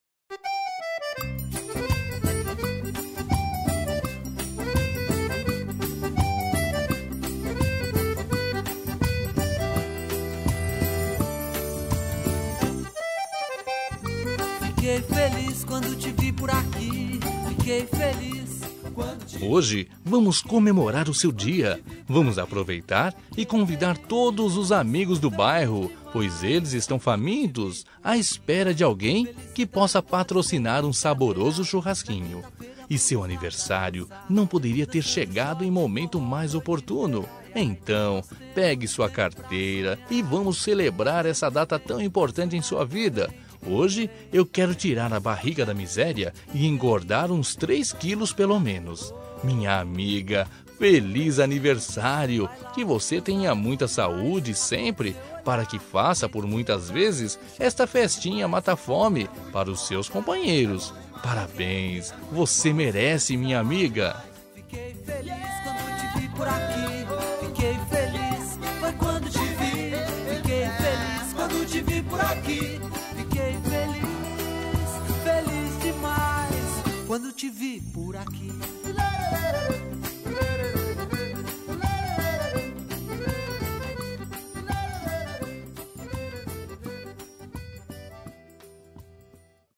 Aniversário de Humor – Voz Masculina- Cód: 200202